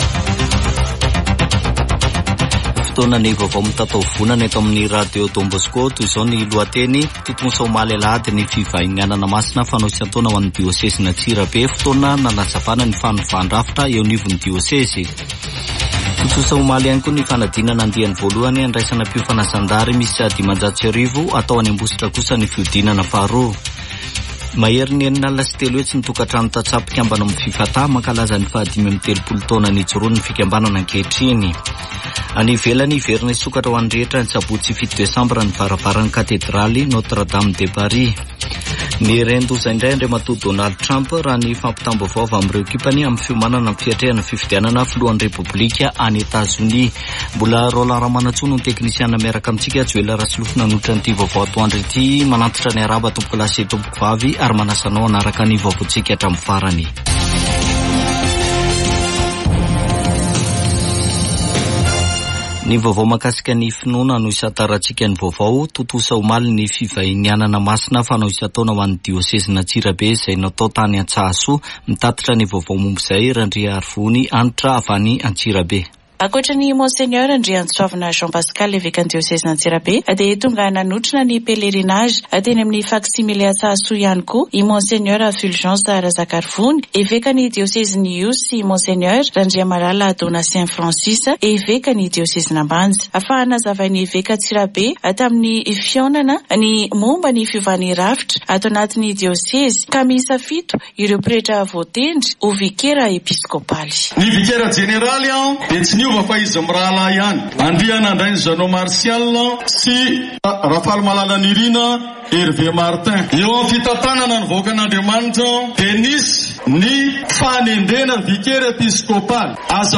[Vaovao antoandro] Alatsinainy 16 septambra 2024